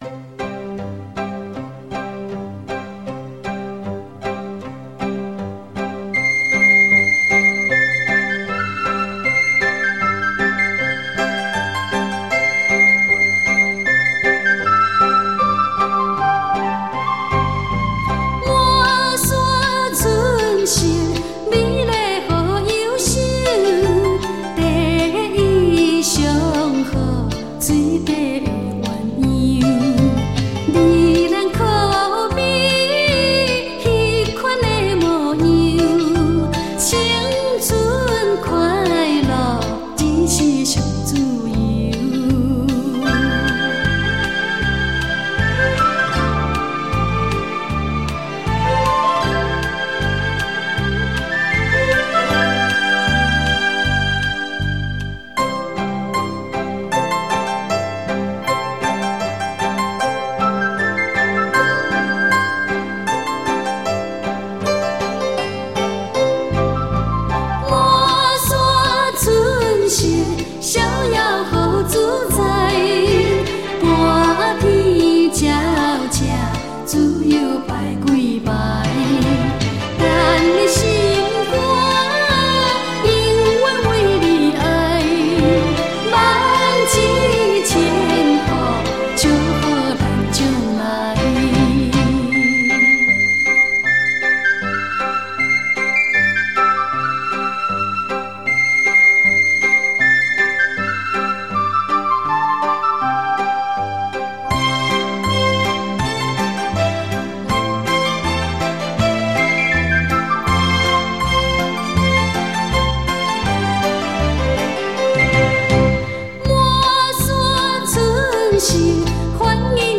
幽雅的嗓音